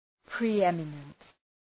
Προφορά
{pri:’emənənt}